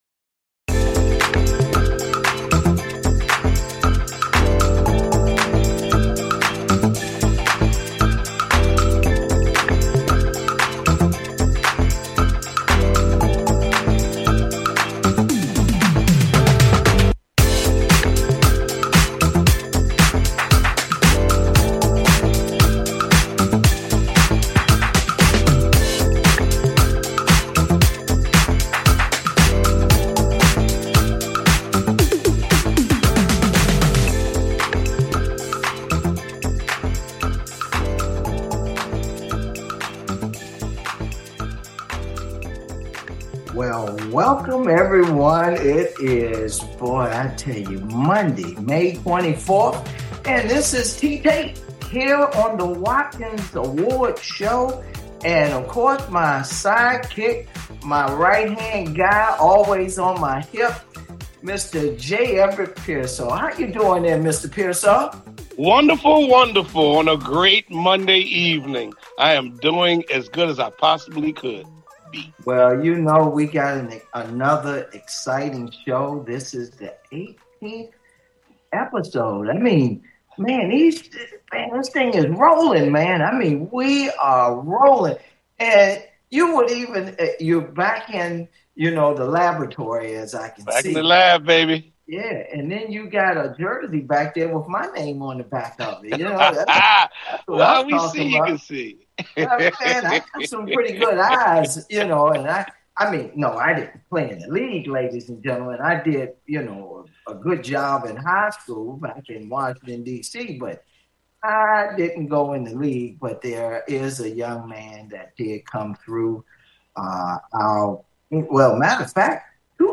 Guest, Justin Quintin Reid is an American football safety for the Houston Texans of the National Football League